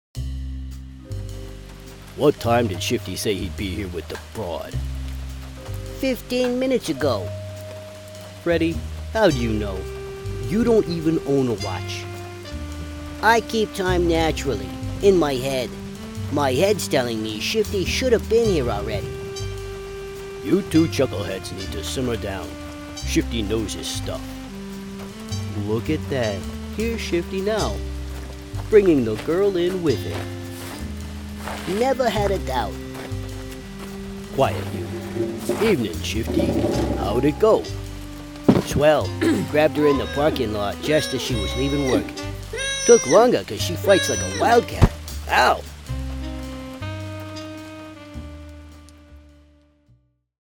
Character Sample